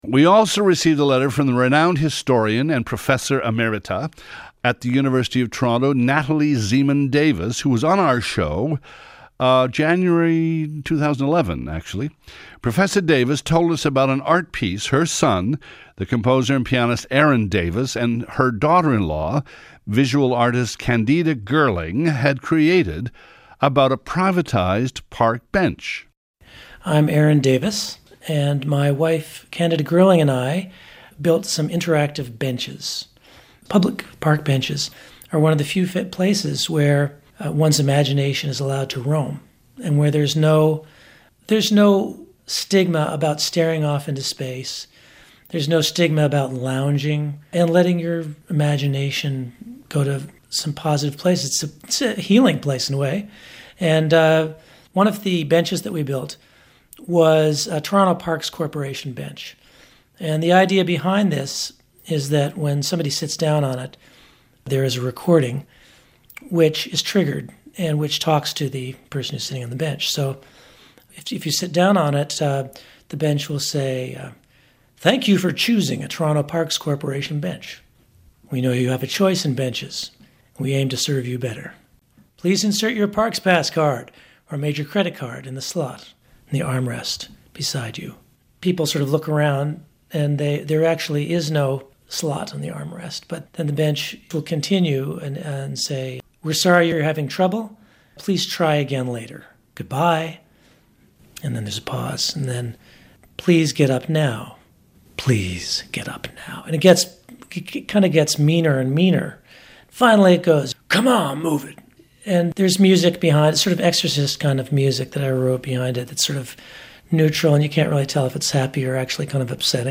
interview on CBC radio